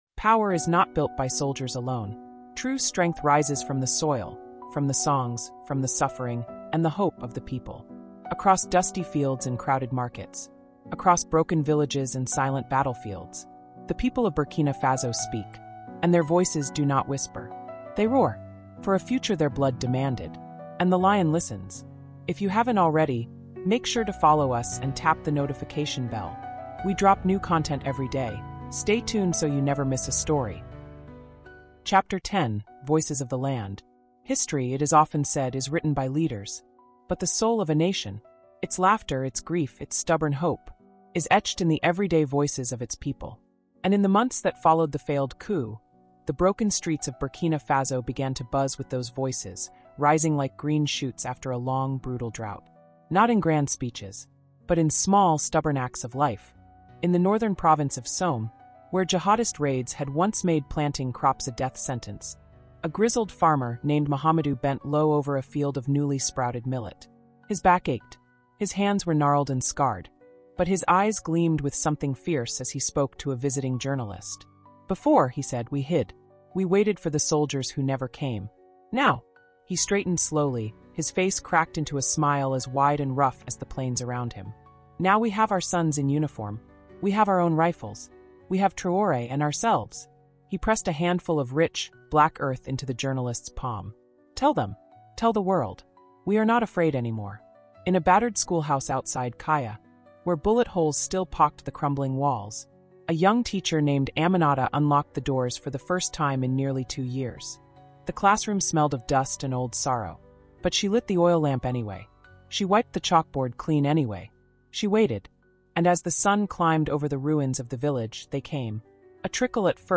Captain Ibrahim Traoré: africa cultural diplomacy (ch10)| Audiobook